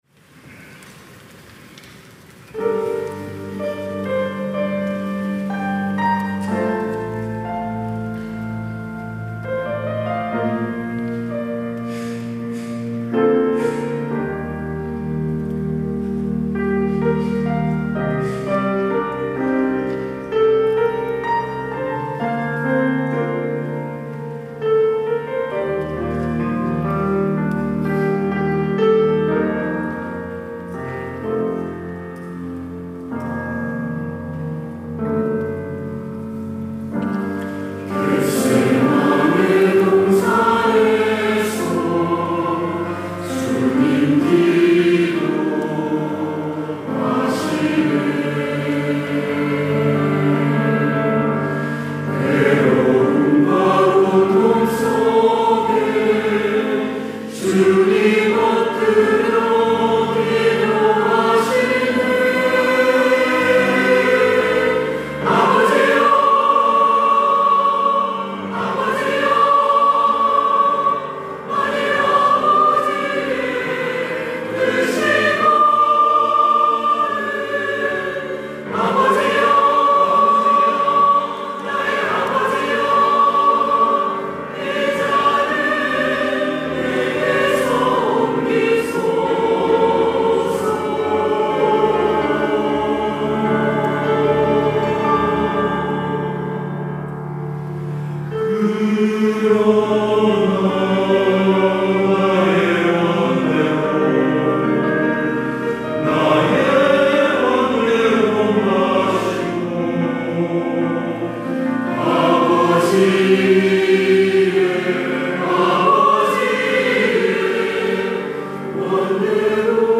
시온(주일1부) - 겟세마네의 기도
찬양대